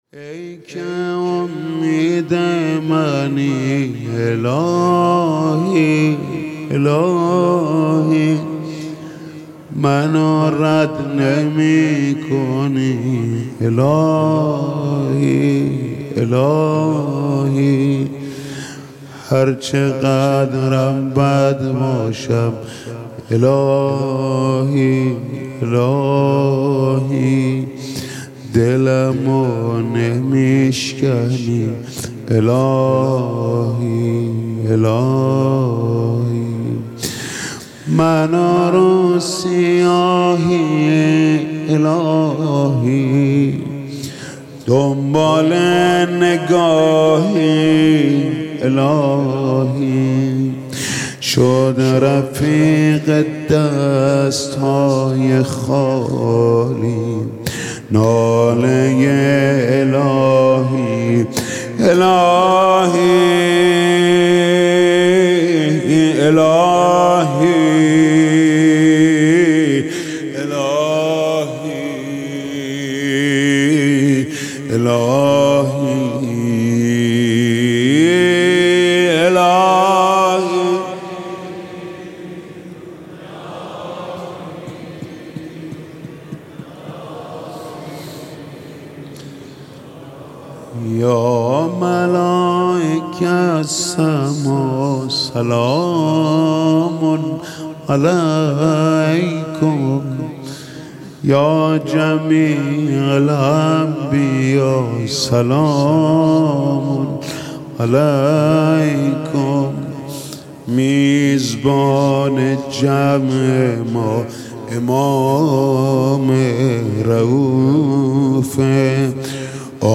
شب بیست و نهم ماه شعبان ١٤٤۲با مدّاحی حاج محمود کریمی، حرم مطهر امام رضا (علیه‌السّلام)